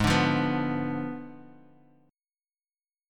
Abdim7 Chord